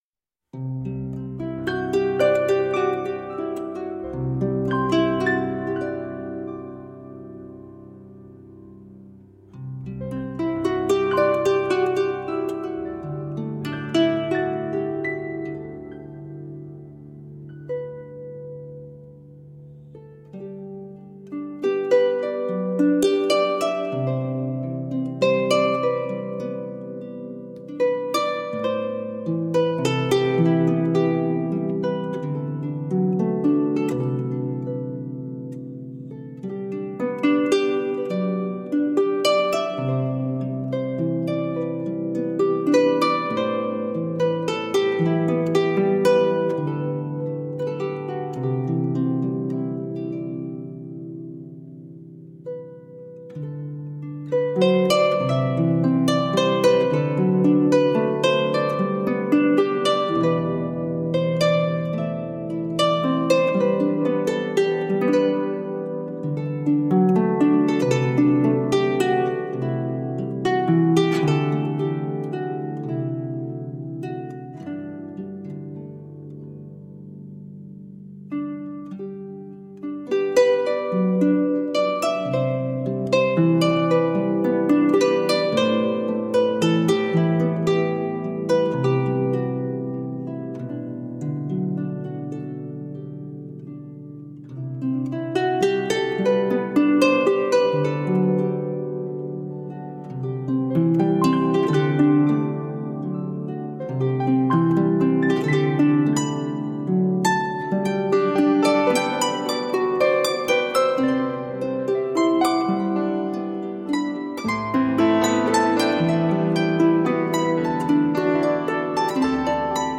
Timeless and enchanting folk music for the soul.
Tagged as: World, Folk, Christmas, Harp